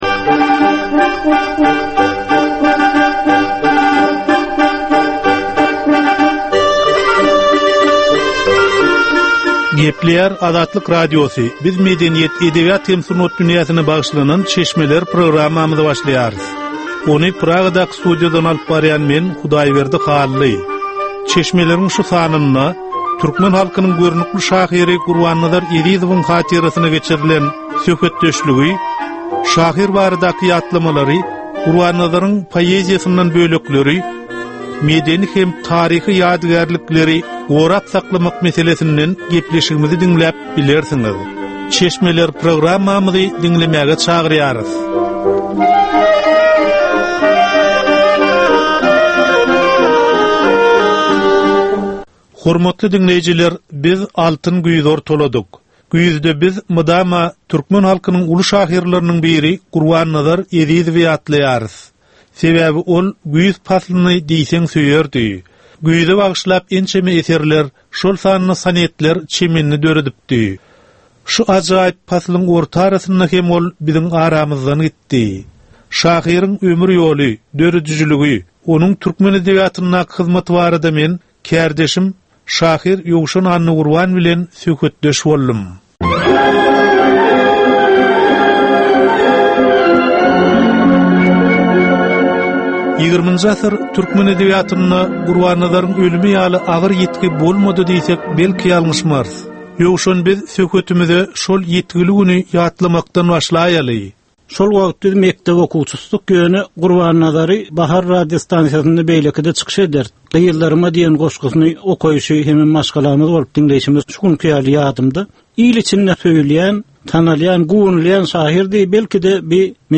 Edebi, medeni we taryhy temalardan 25 minutlyk ýörite geplesik.